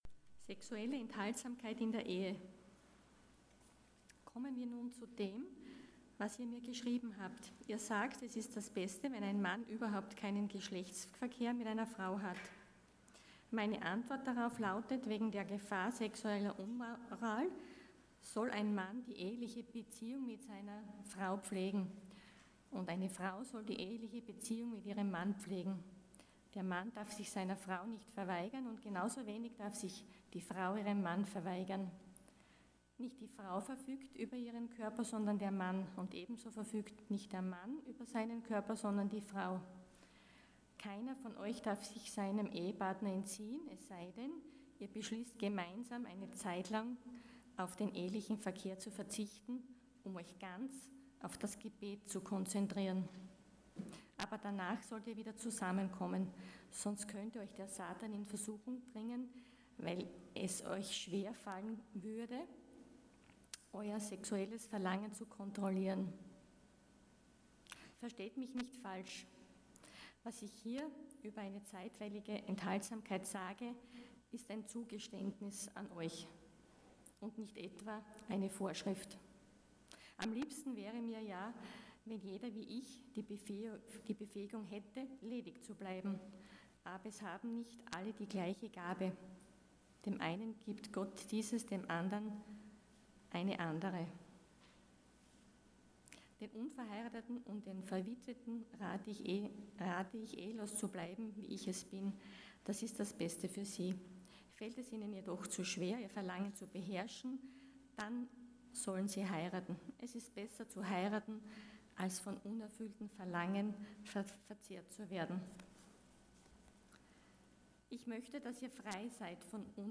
Prediger
Dienstart: Sonntag Morgen